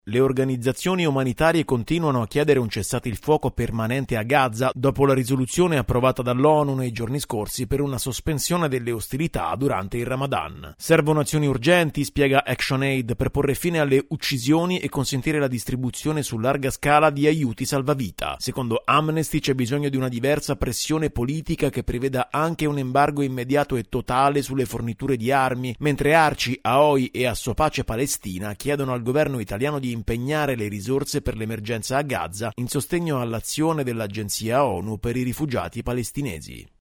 Le organizzazioni umanitarie chiedono un cessate il fuoco permanente nella Striscia di Gaza. Il Servizio